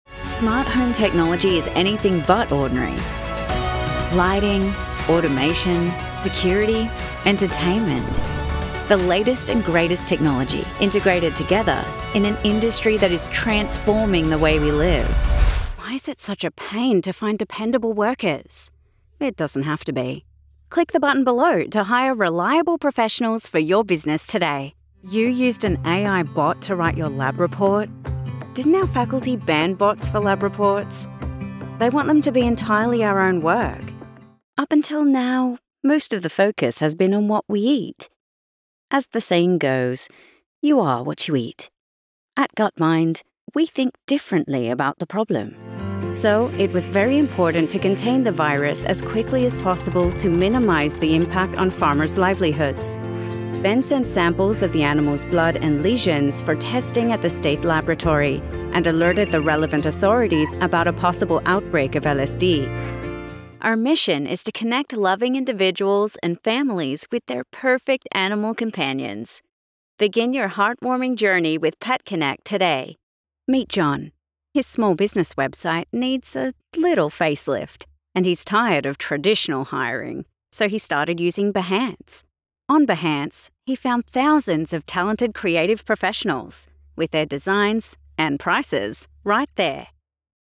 Englisch (Australien)
Natürlich, Vielseitig, Freundlich, Unverwechselbar, Kommerziell
Unternehmensvideo